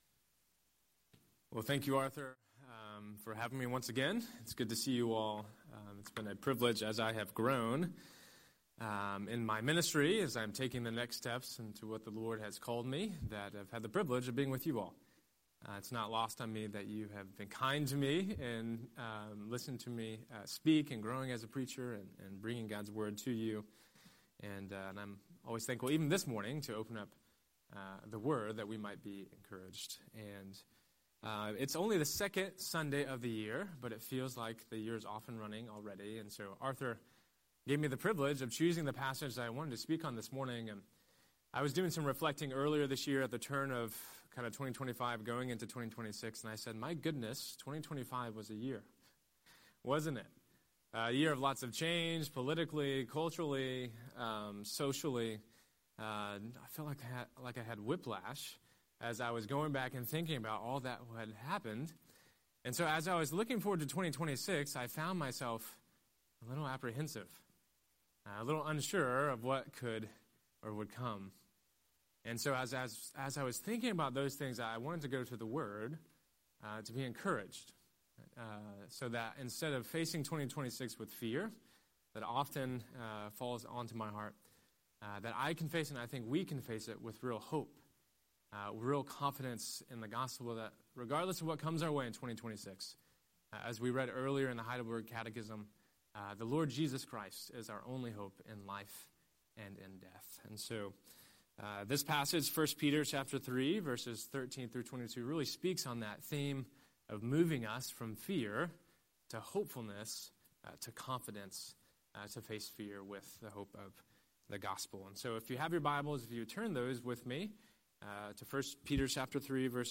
Scripture: 1 Peter 3:13–22 Series: Sunday Sermon